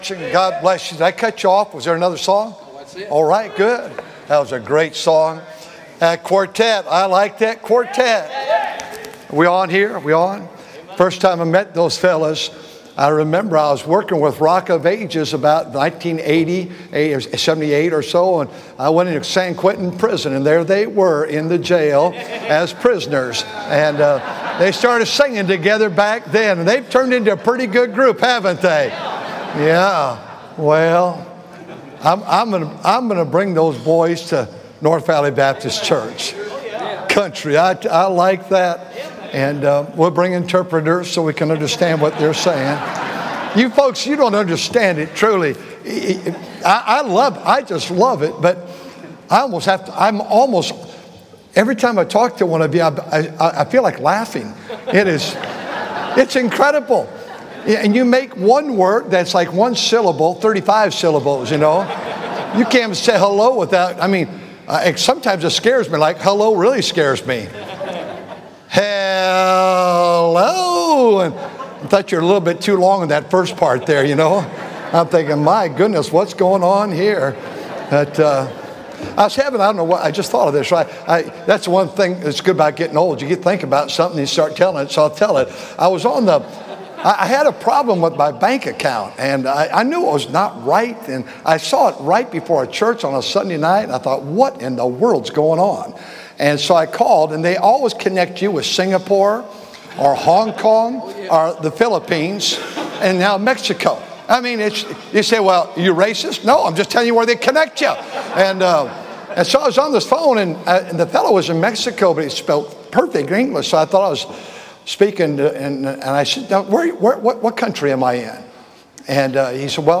A sermon preached Monday Evening during our Spring Jubilee, on March 24, 2025.